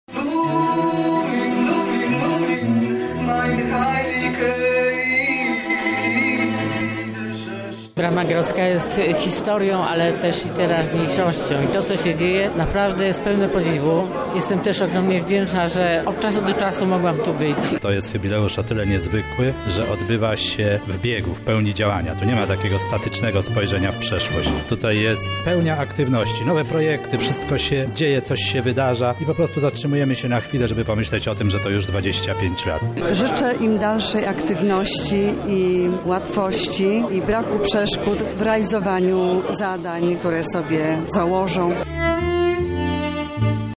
Zapytaliśmy lublinian czym dla nich jest Teatr NN.